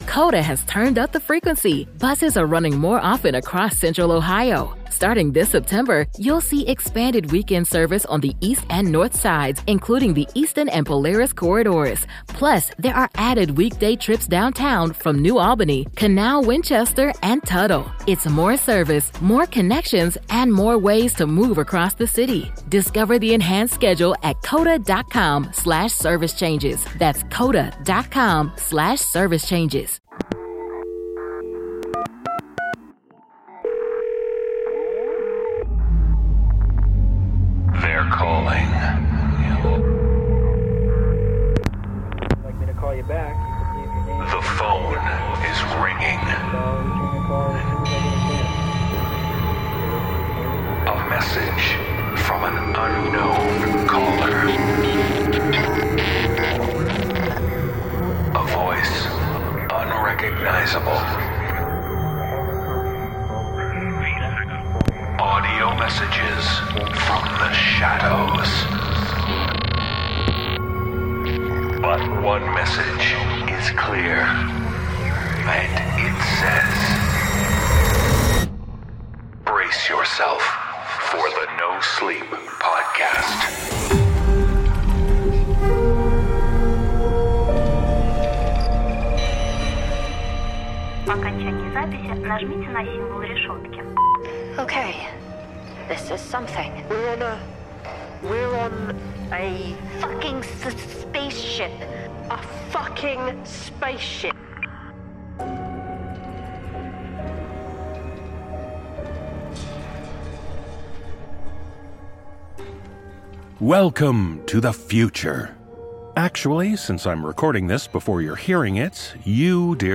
The voices are calling with tales of futuristic fears.